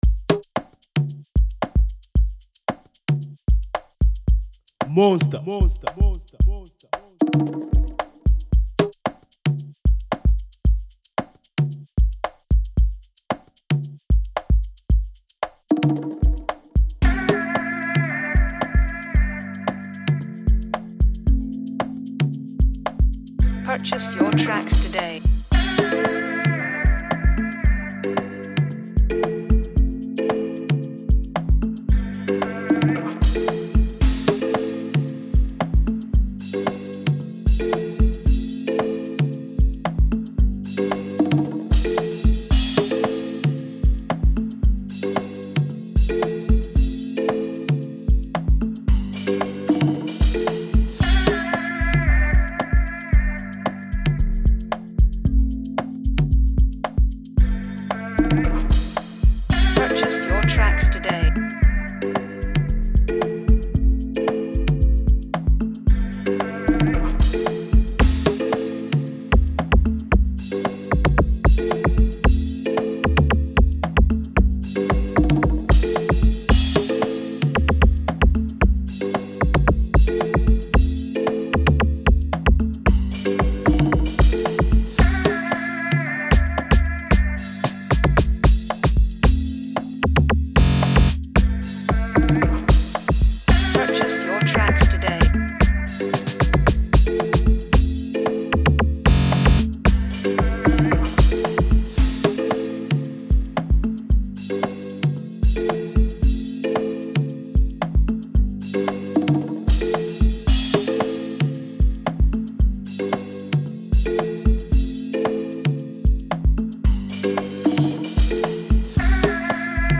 amapiano instrumental